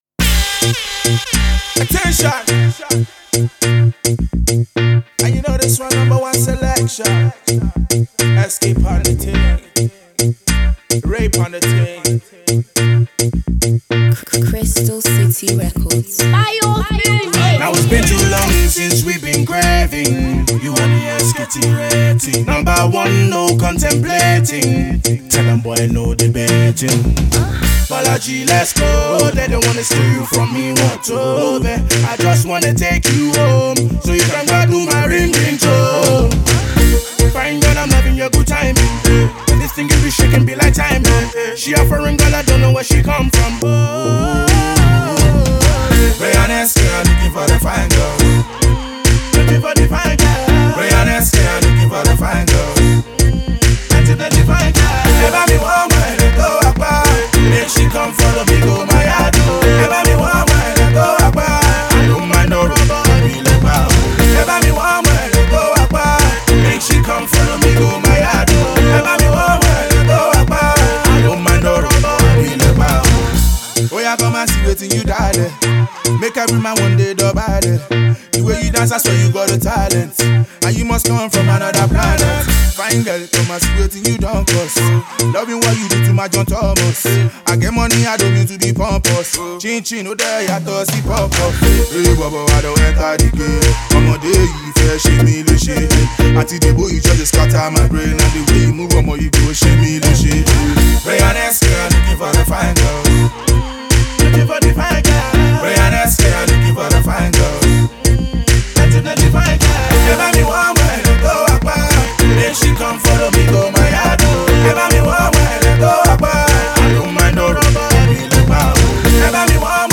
Dancehall, Pop
the perfect summer song